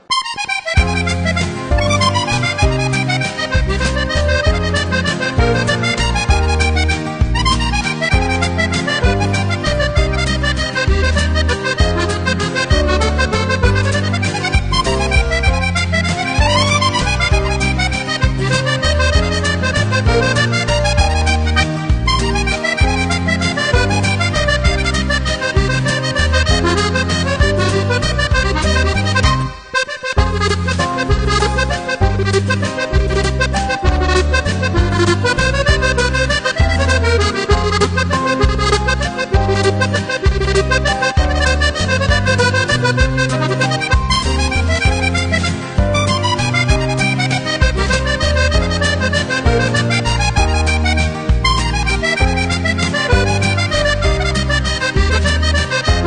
Titres enchain?s pour danser